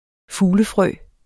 Udtale [ ˈfuːlə- ]